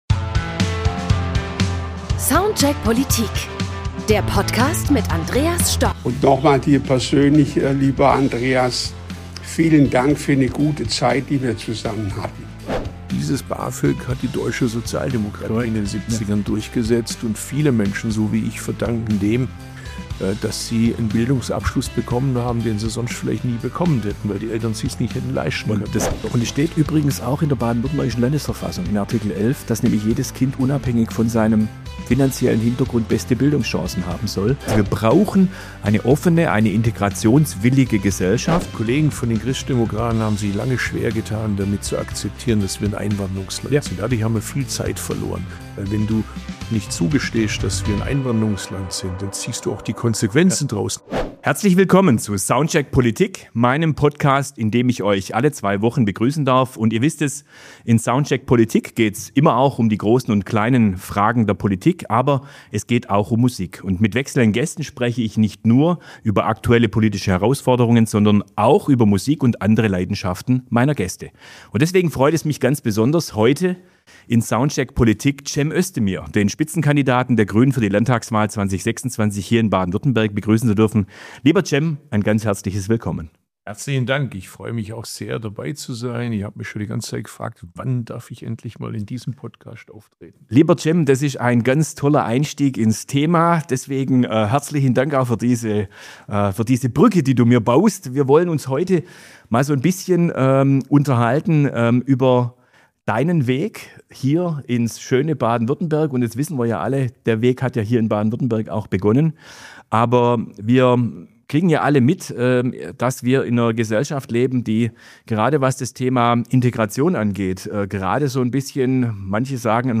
Beschreibung vor 4 Monaten In dieser Folge von Soundcheck Politik spricht der SPD-Fraktionsvorsitzende Andreas Stoch mit Cem Özdemir, dem Spitzenkandidaten von Bündnis 90/Die Grünen zur Landtagswahl 2026 in Baden-Württemberg. Andreas Stoch spricht mit Cem Özdemir über seinen Bildungsweg als Kind von Gastarbeitereltern auf der Schwäbischen Alb, über das sozialdemokratische Aufstiegsversprechen, über Integration in einer Einwanderungsgesellschaft und darüber, warum wir gerade jetzt eine demokratische Streitkultur brauchen, die nicht den Extremen das Feld überlässt.